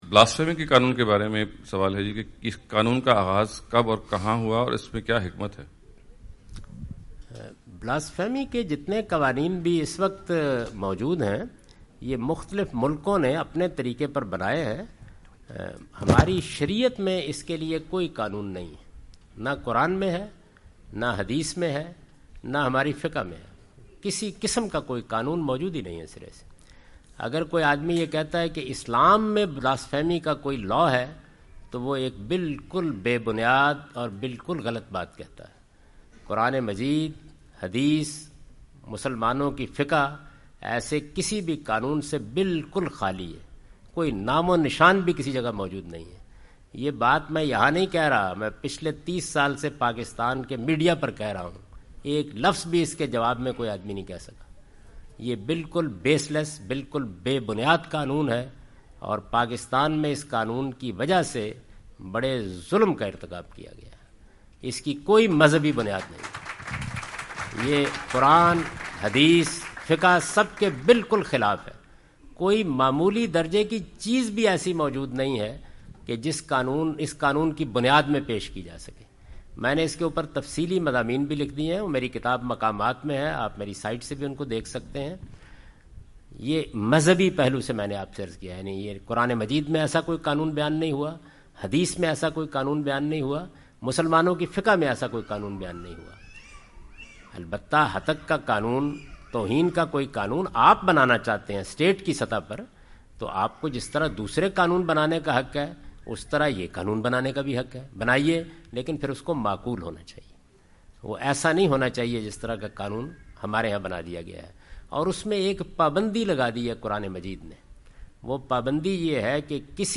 Javed Ahmad Ghamidi answer the question about "Islam and Blasphemy Laws" during his US visit.
جاوید احمد غامدی اپنے دورہ امریکہ کے دوران ڈیلس۔ ٹیکساس میں "اسلام اور توہین مذہب کے قوانین" سے متعلق ایک سوال کا جواب دے رہے ہیں۔